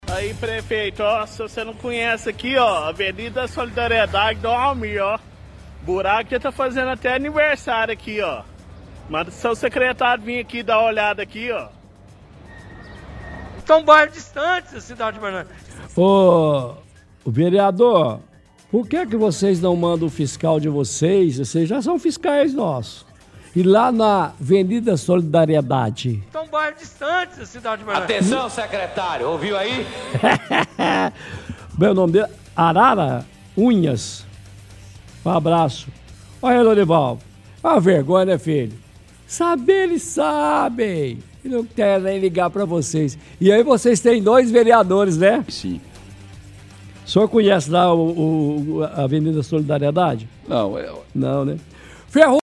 – Ouvinte reclama buraco na Av. Solidariedade fala que está fazendo aniversário e pede solução para prefeito e vereadores